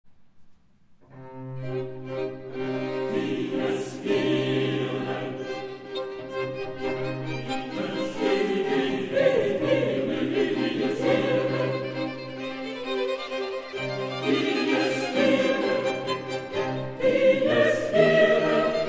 — Ausschnitte aus dem Konzert der Kaufbeurer Martinsfinken in Irsee vom 21.3.10: